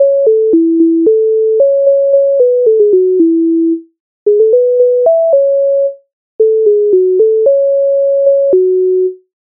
MIDI файл завантажено в тональності A-dur
Ой сів поїхав Українська народна пісня з обробок Леонтовича с. 149
Ukrainska_narodna_pisnia_Oj_siv_poikhav.mp3